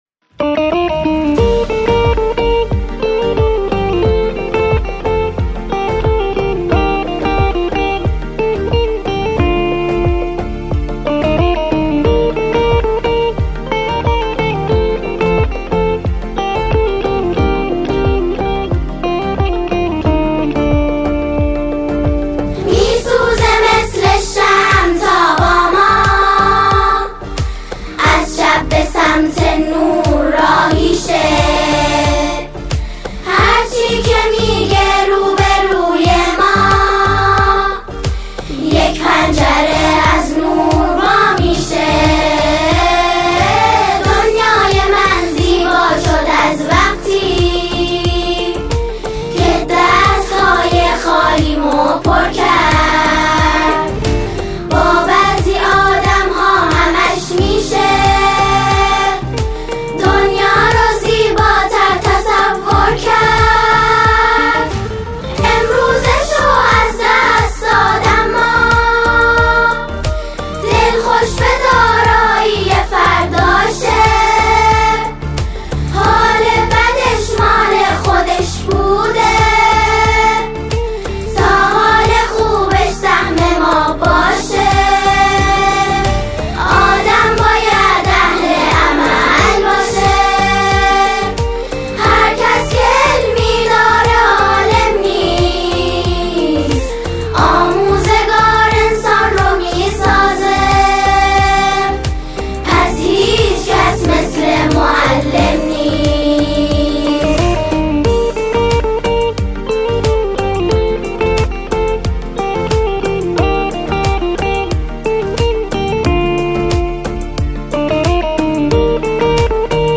سرودهای روز معلم